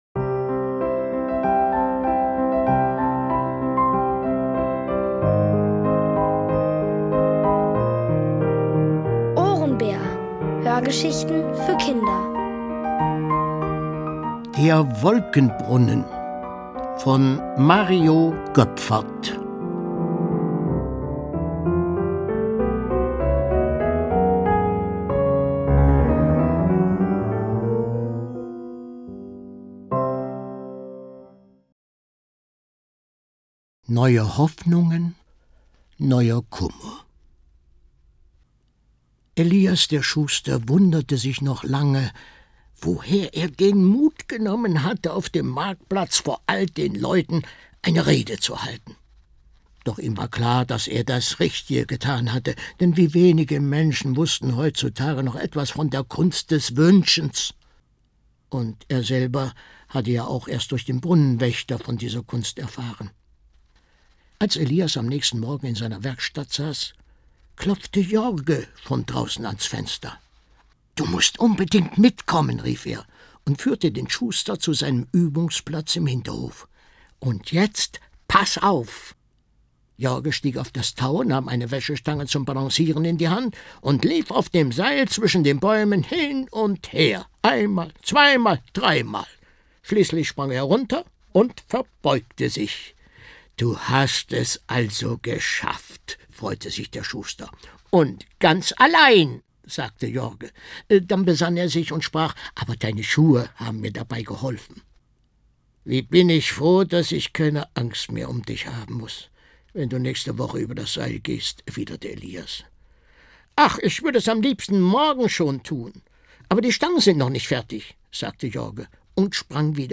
Von Autoren extra für die Reihe geschrieben und von bekannten Schauspielern gelesen.
Es liest: Horst Bollmann.